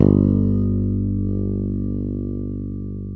Index of /90_sSampleCDs/East Collexion - Bass S3000/Partition A/FRETLESS-A